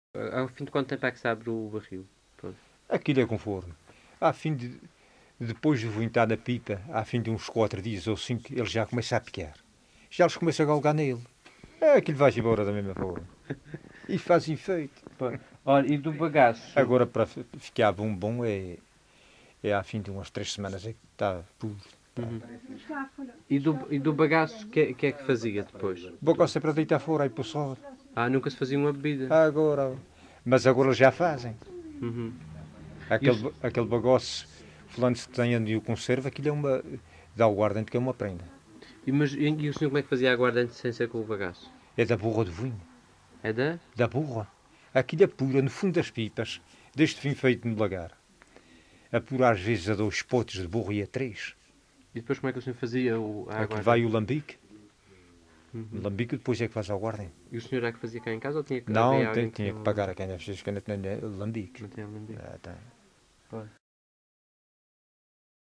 LocalidadeCovas (Santa Cruz da Graciosa, Angra do Heroísmo)